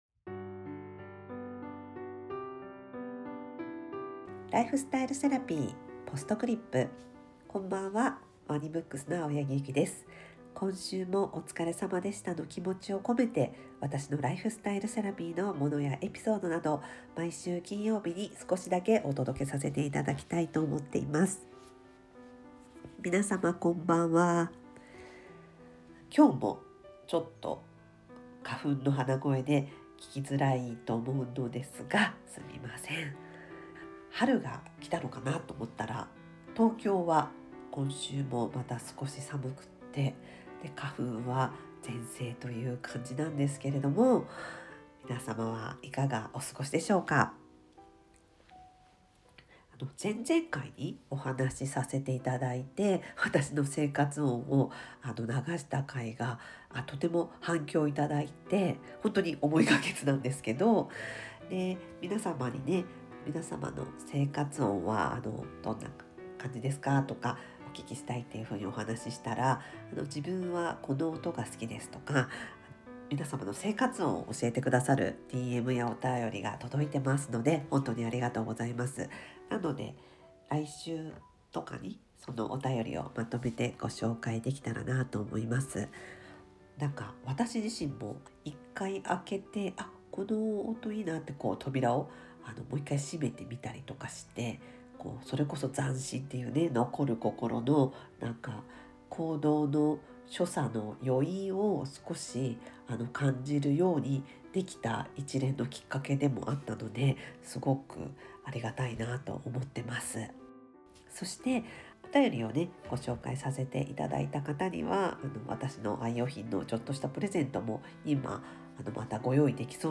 BGM／MusMus